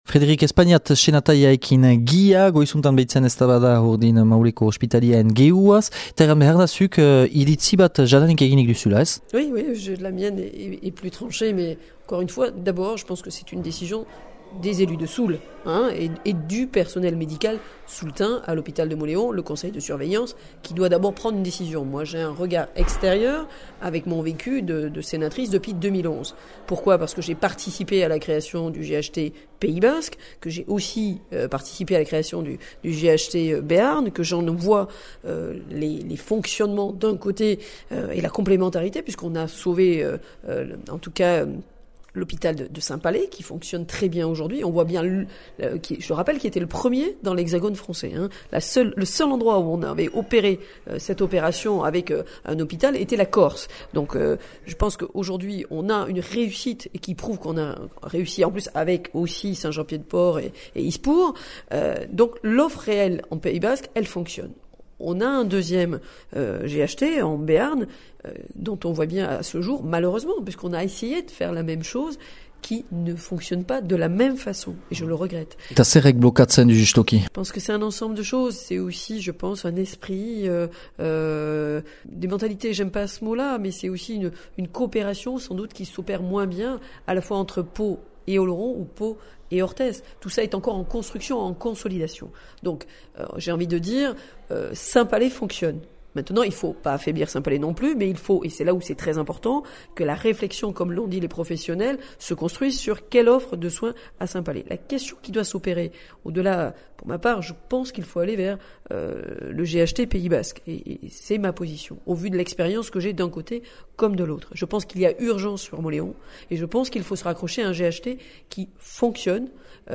Üngürgiro hortan dü antolatü EH Bai alderdiak Ospitalearen geroaz mahain üngürü bat neskenegünean.
Frederique Espagnac senataria :